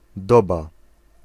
Ääntäminen
IPA : /ˈɪə̯ɹ.ə/ IPA : /ˈɛɹ.ə/